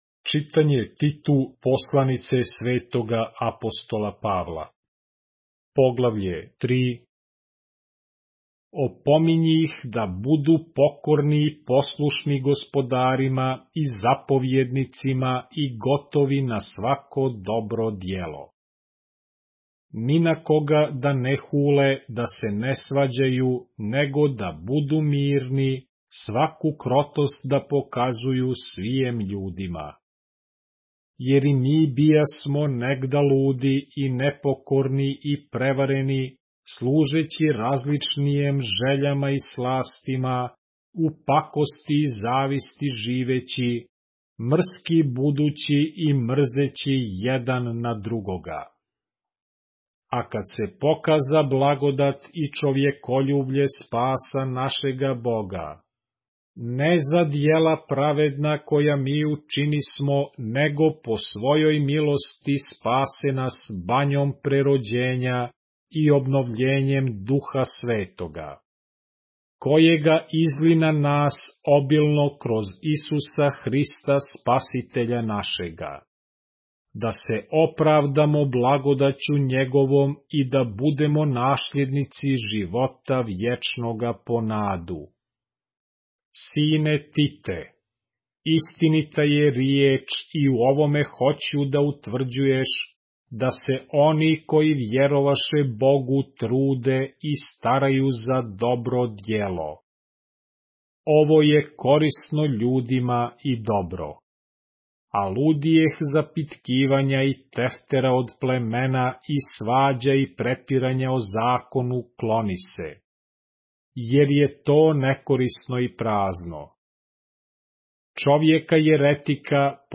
поглавље српске Библије - са аудио нарације - Titus, chapter 3 of the Holy Bible in the Serbian language